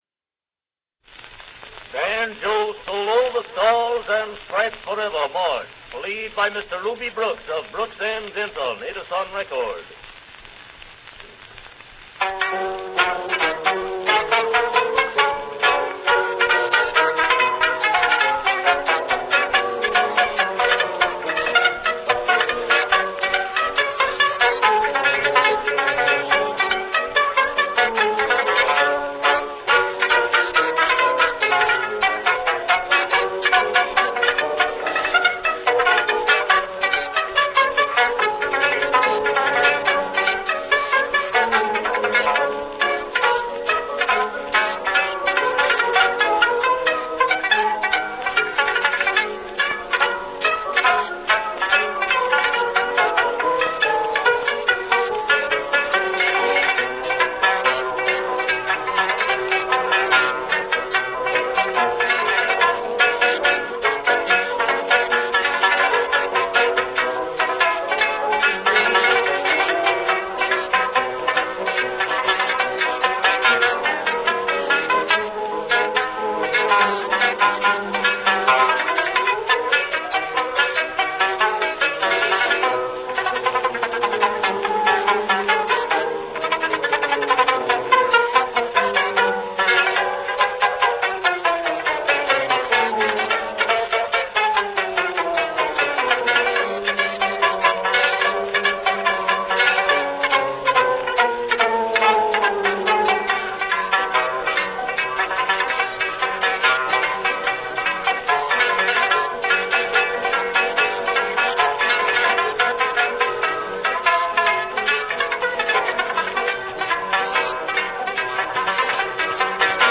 "The Stars and Stripes Forever" march
banjo) (RealAudio file from a  wax cylinder recording at Early Recorded Sounds and Wax Cylinders.